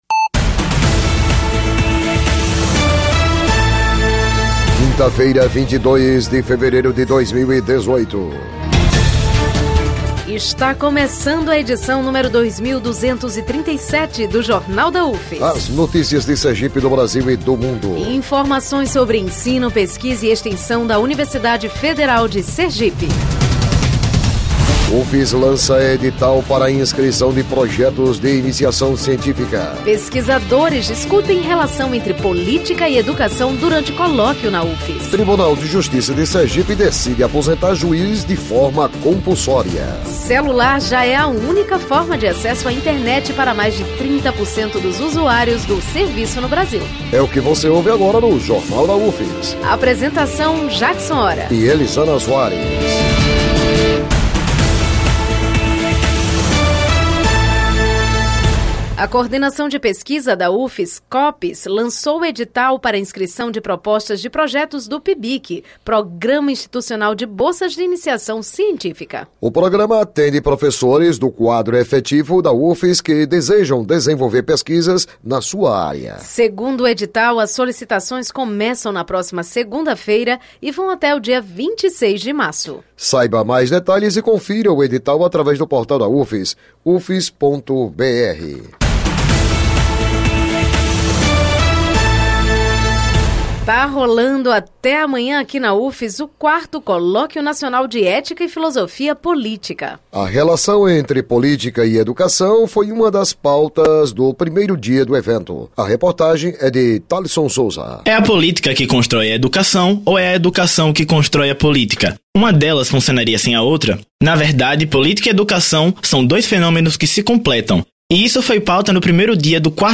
O Jornal da UFS desta quinta-feira, 23, repercute a abertura do Colóquio Nacional de Ética e Filosofia Política. O evento acontece na UFS pela quarta vez, sendo que a relação política e educação foi uma das pautas do primeiro dia de programação. O noticiário vai ao ar às 11h00 na Rádio UFS FM, com reprises às 17h00 e 22h00.